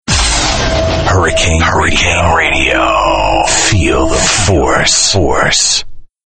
RADIO IMAGING